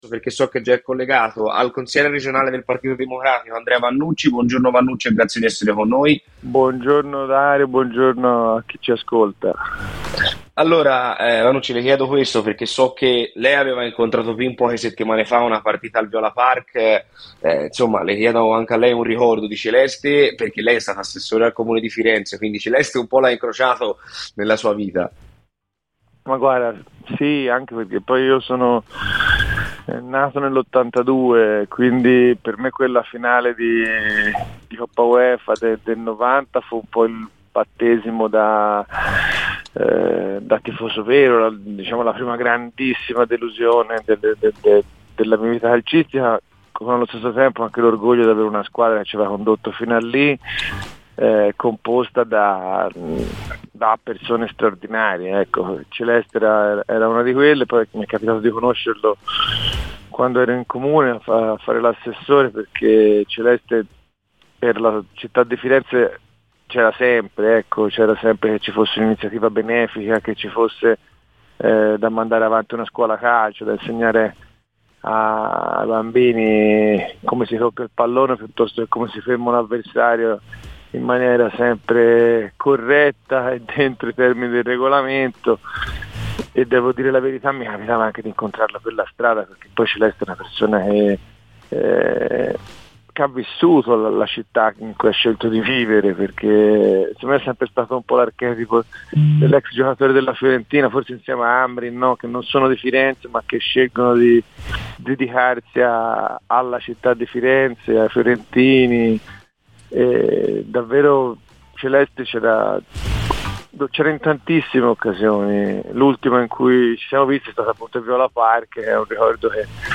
Il Consigliere Regionale del Partito Democratico e tifoso della Fiorentina Andrea Vannucci ha parlato stamani a Radio Firenzeviola, durante 'C'è polemica'.